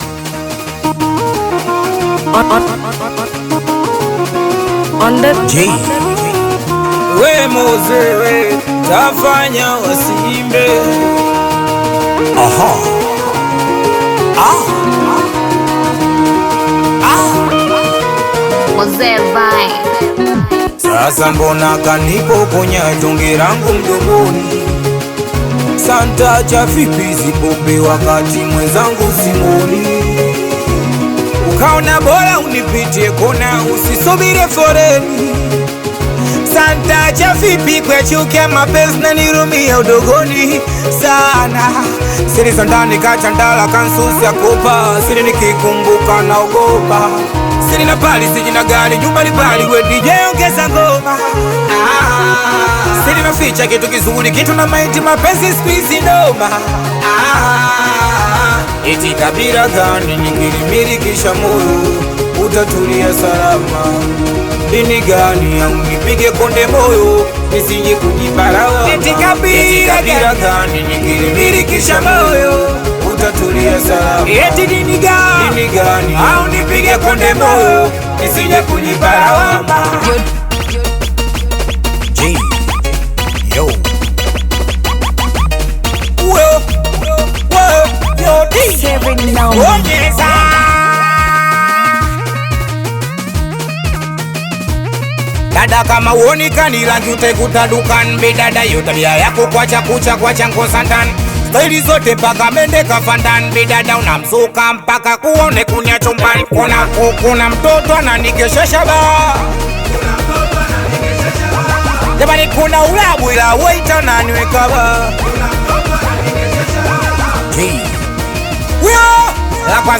AudioSingeli
high-energy Afrobeat/Singeli single